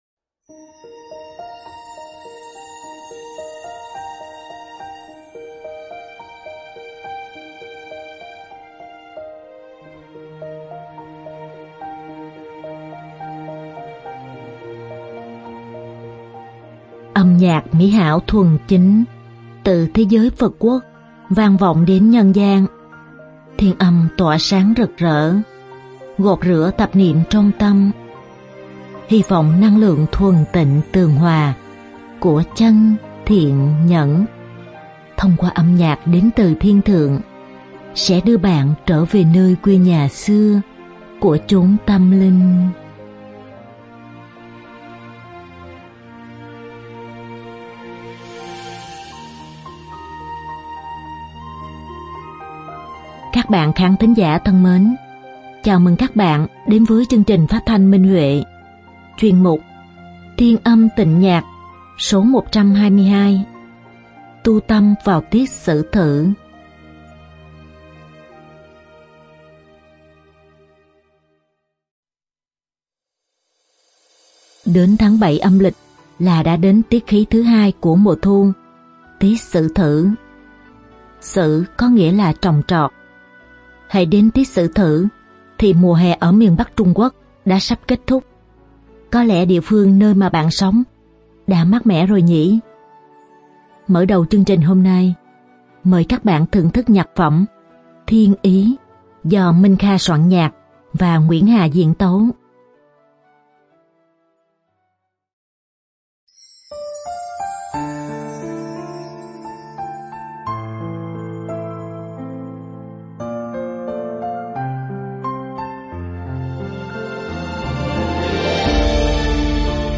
Đơn ca nữ
Song ca